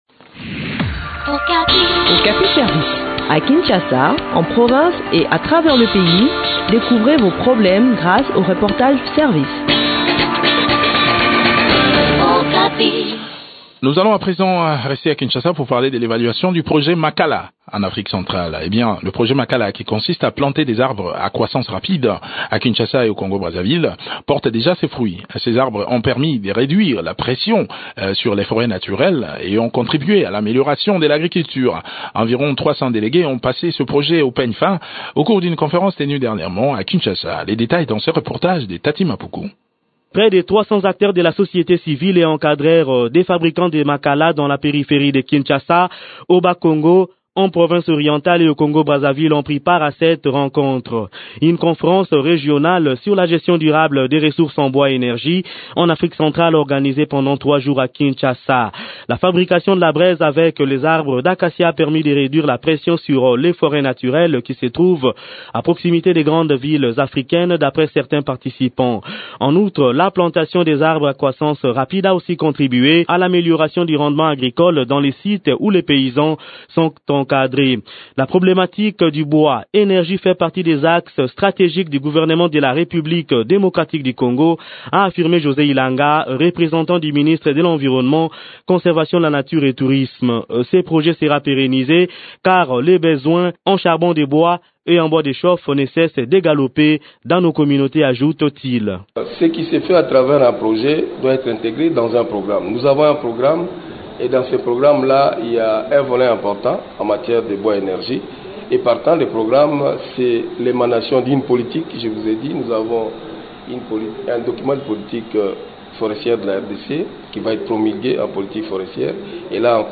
Le point sur l’exécution de ce projet dans cet entretien